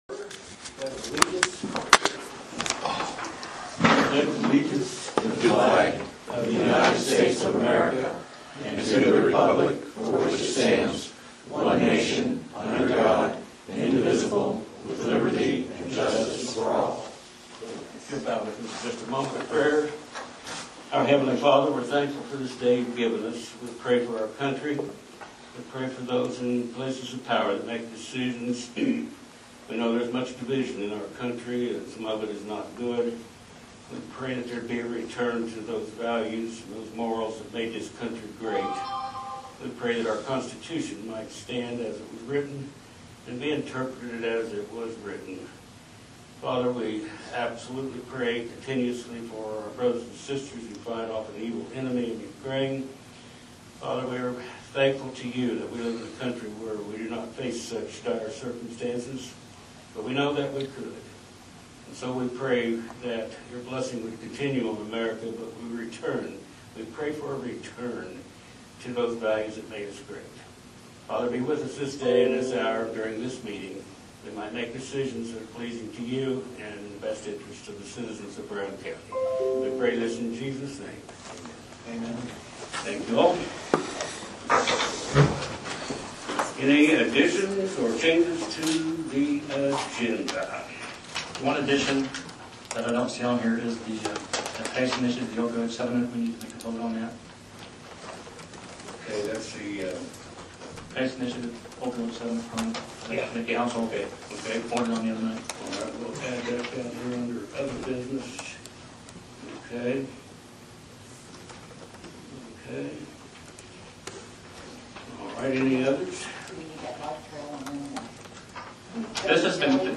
Commissioner Meeting Notes, Mar 1, 2023
comm-meeting-mar-1-2023.mp3